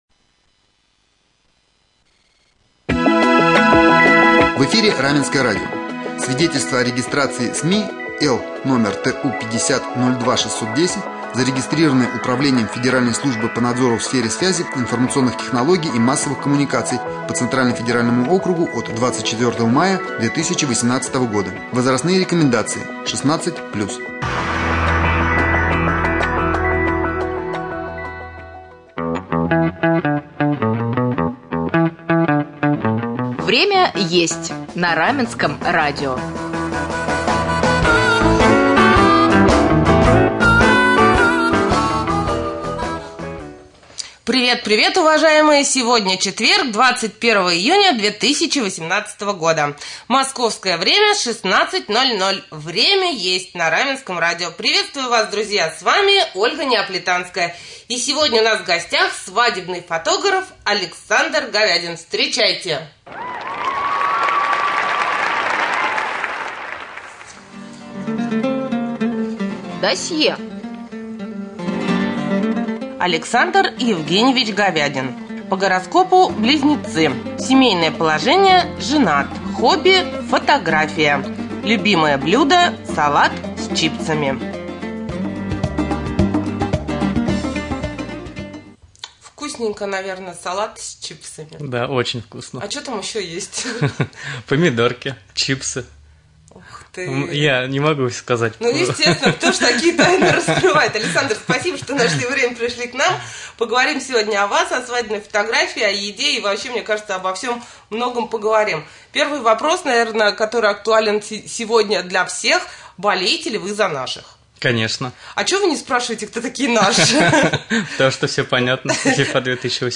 Гость студии